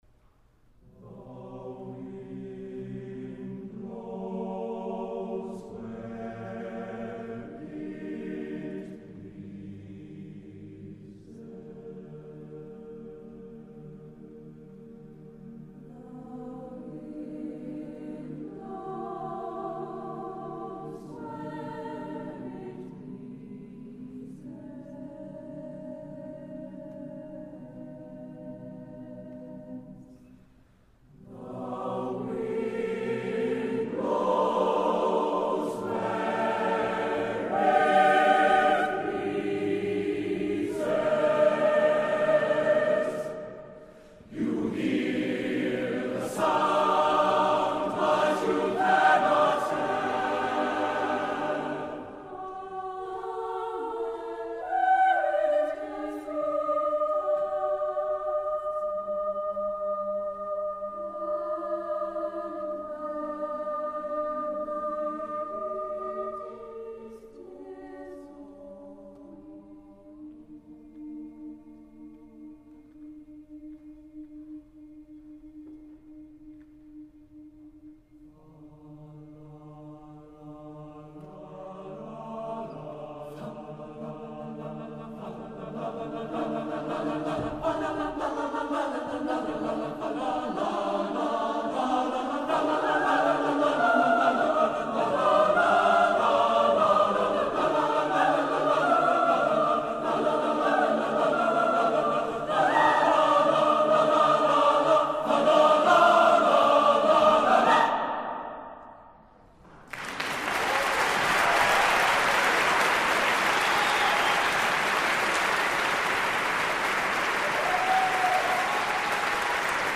Voicing: SSAATTBB